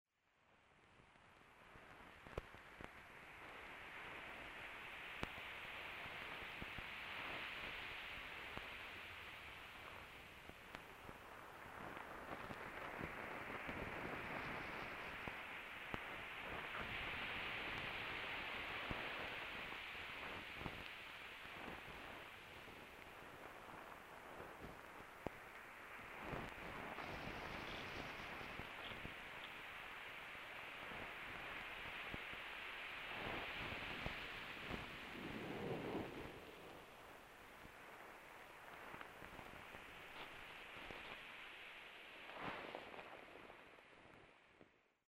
a friend once commented how many of my recordings are from a perspective up close. for me it is this perspective that I return to and find is important, an audible view of a situation – in this case a continuous event – that is not readily audible, or at least is greatly enhanced through amplification from the contact mic surface. for this release movement of water in two forms become the basis of exploration. one active one passive. each revealing some inherent noise, compression, hum and tension of existence.
Field Recording Series by Gruenrekorder
The source sounds are streams of water, rain, crackles that seem stones moved by the force of the water, wind blowing and birds.